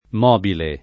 [ m 0 bile ]